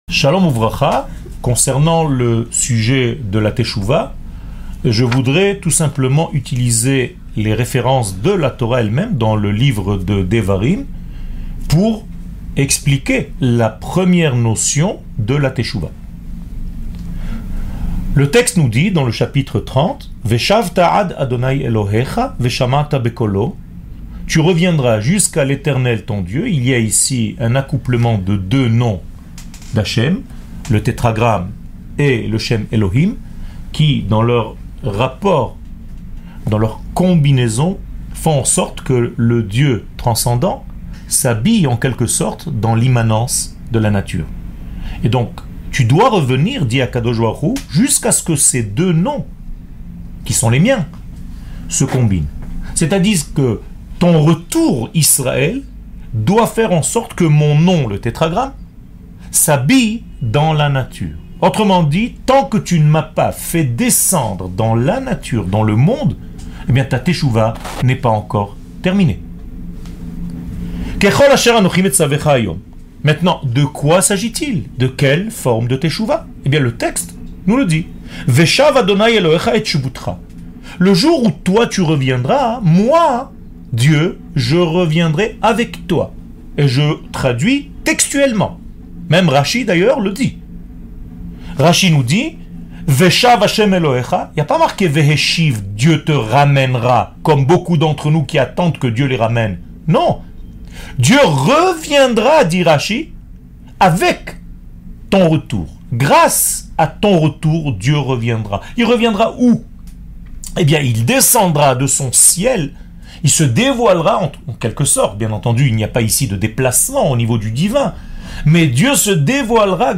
שיעור מ 19 ספטמבר 2016